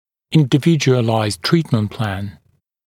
[ˌɪndɪ’vɪʤuəlaɪzd ‘triːtmənt plæn][ˌинди’виджуэлайзд ‘три:тмэнт плэн]индивидуальный план лечения